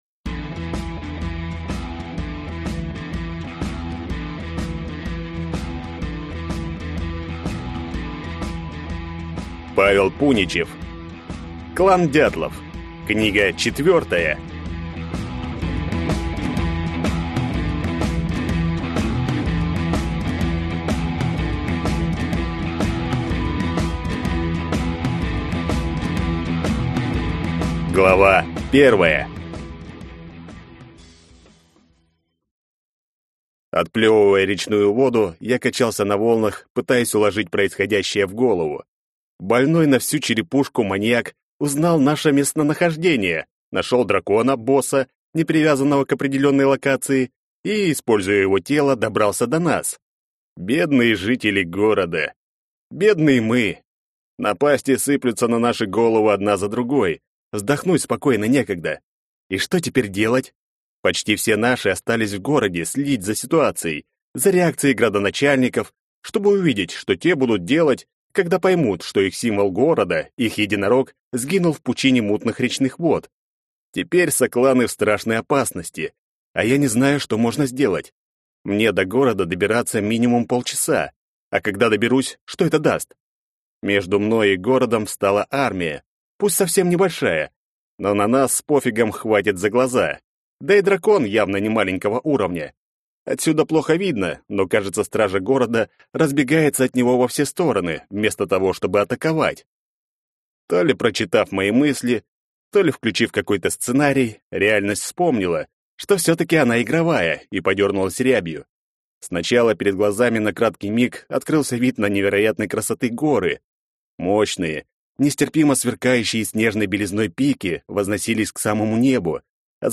Аудиокнига Клан «Дятлов». Книга 4 | Библиотека аудиокниг